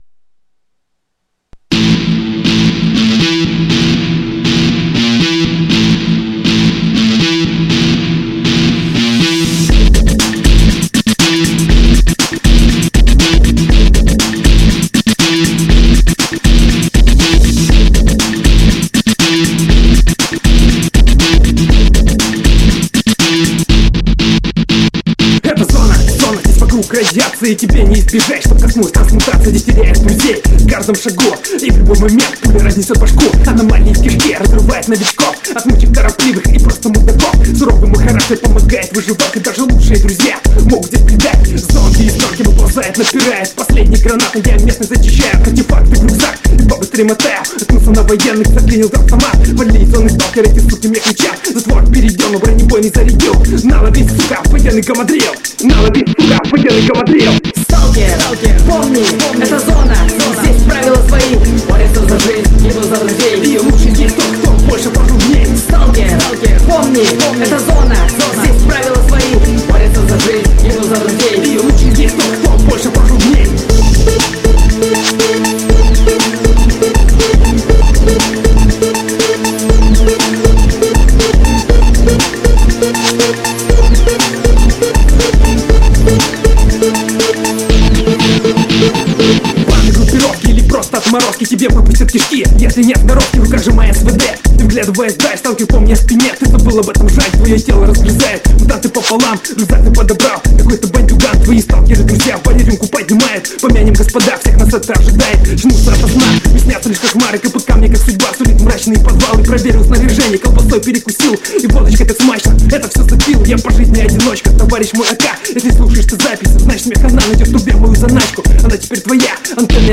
Реп сталкер [9]
Отличная, достойная и динамичная песня сталкер!